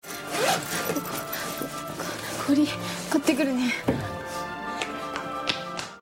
Kita akan mengangkat salah satu adegan dari dorama Nobuta o Produce episode 7 yang menggunakan -te kuru berkali-kali.